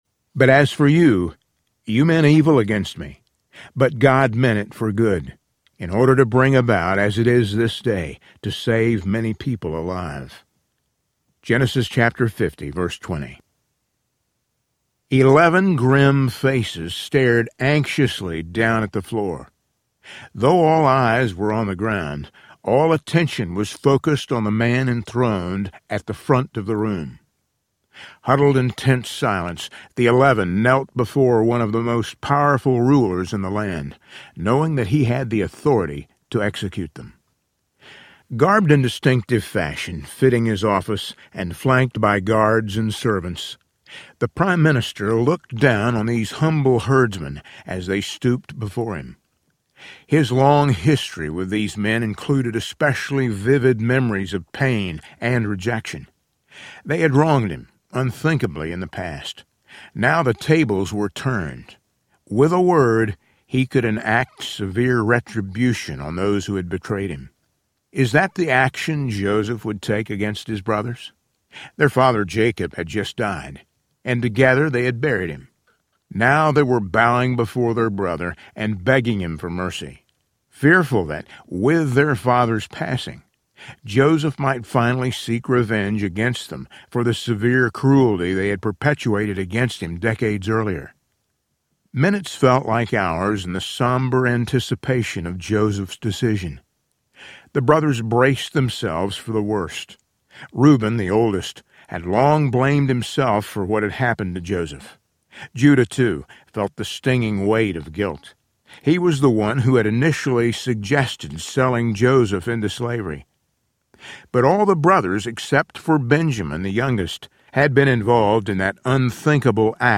Twelve Unlikely Heroes Audiobook
8.1 Hrs. – Unabridged